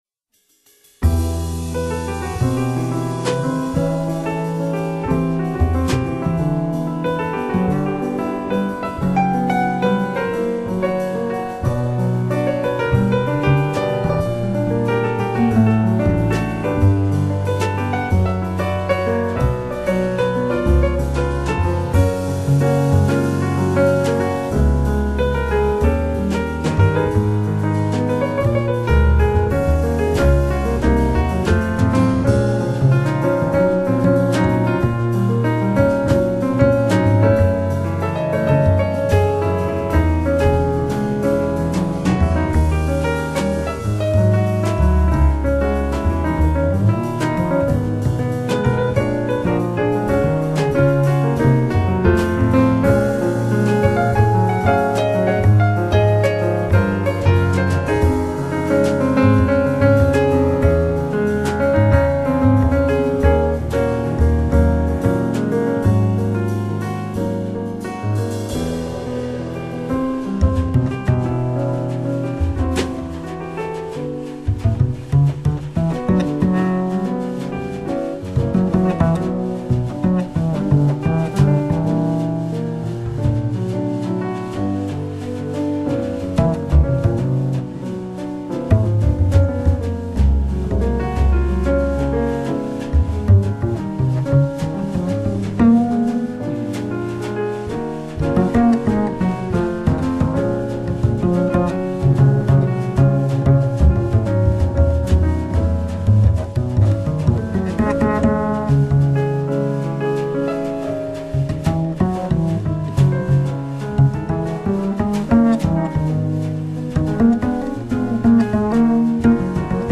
멜로디가 아름다운 재즈피아노입니다